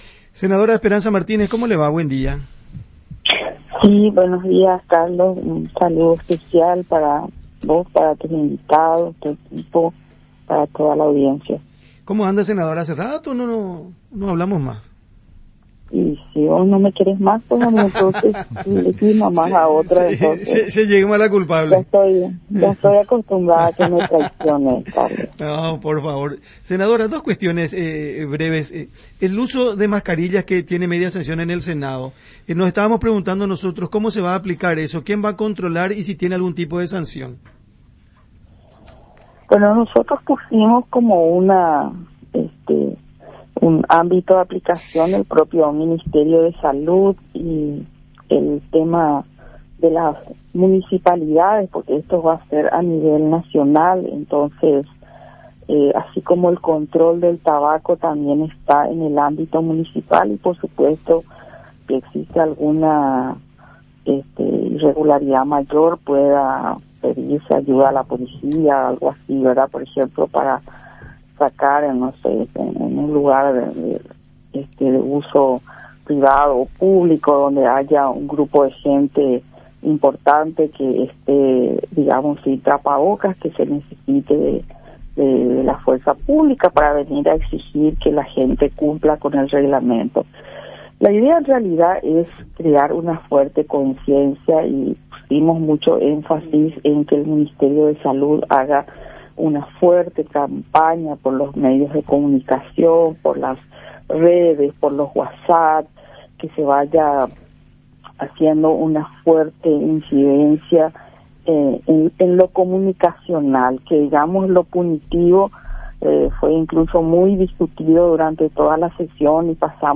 Senadora habla de posible desabastecimiento en Salud